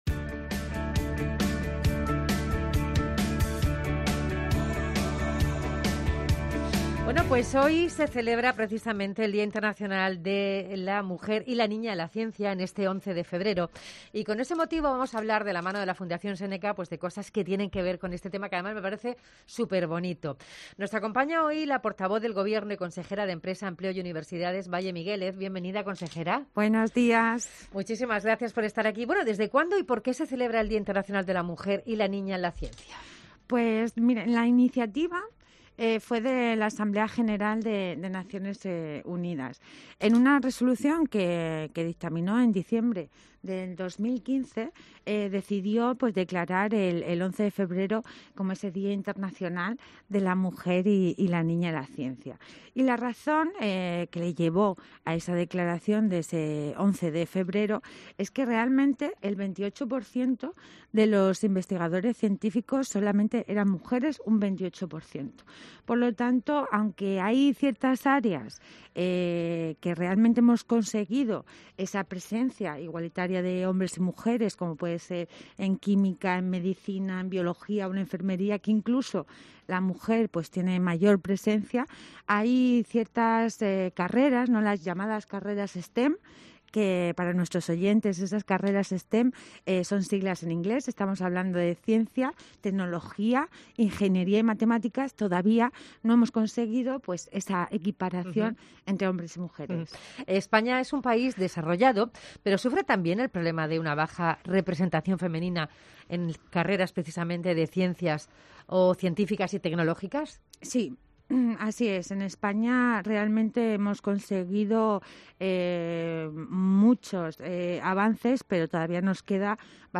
La Consejera nos habla sobre la diferencias existentes entre hombres y mujeres en la investigación con motivo de la celebración del Dia de la Mujer y la Niña en la Ciencia